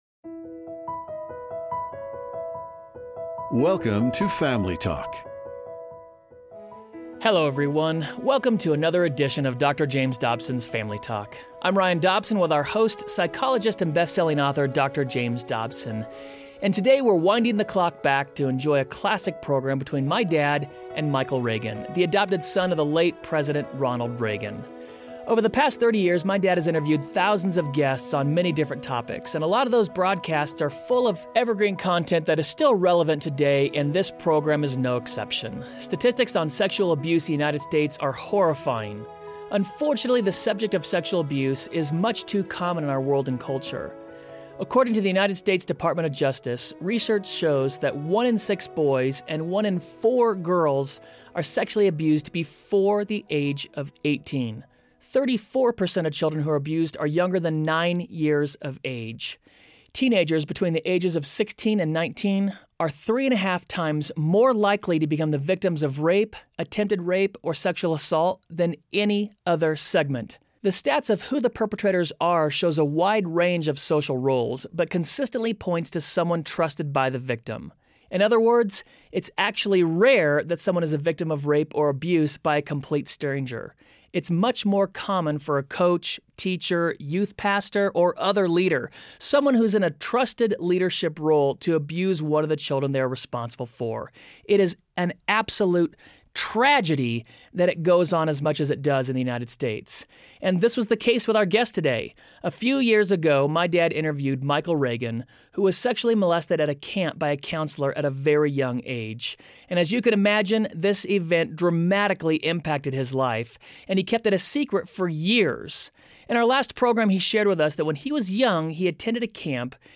Have you suffered abuse at the hands of another? Dr. Dobson interviews Michael Reagan about how God healed him from abuse involving a camp counselor, and how He equipped him to help others.